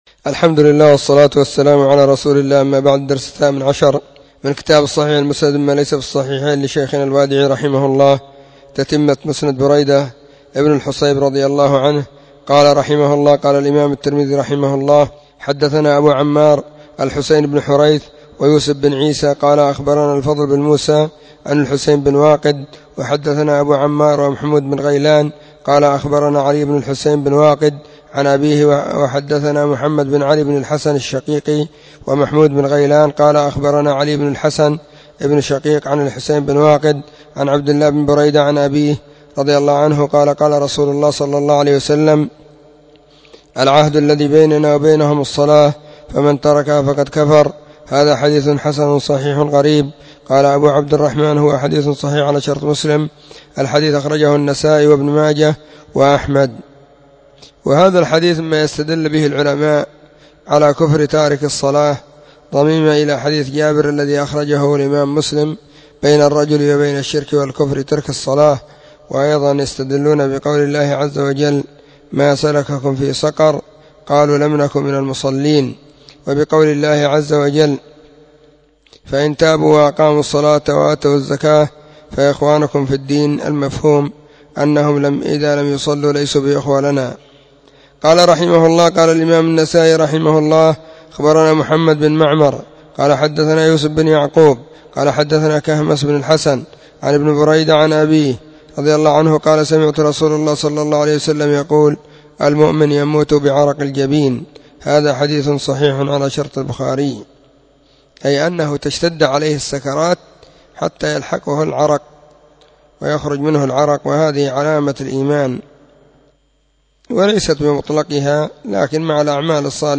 📖 الصحيح المسند مما ليس في الصحيحين = الدرس: 18